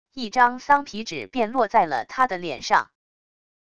一张桑皮纸便落在了他的脸上wav音频生成系统WAV Audio Player